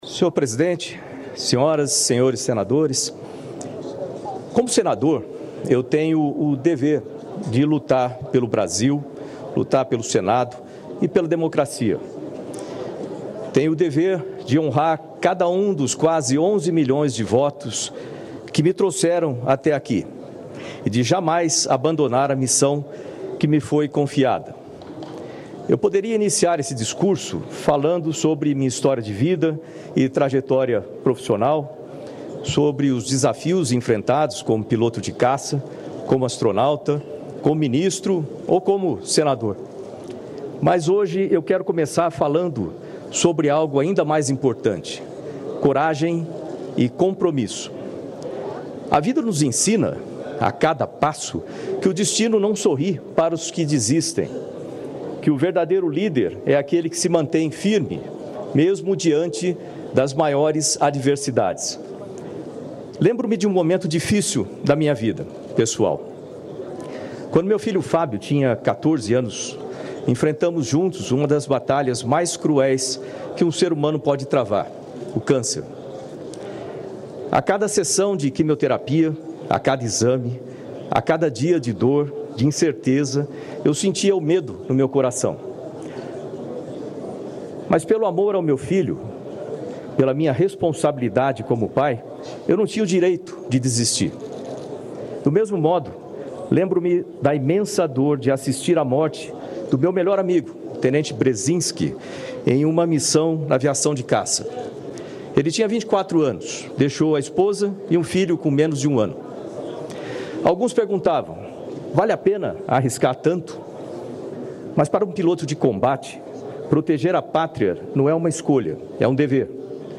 Discurso do candidato Astronauta Marcos Pontes
O senador Astronauta Marcos Pontes (PL-SP) foi o primeiro dos candidatos à presidência do Senado a discursar na reunião preparatória deste sábado (1º).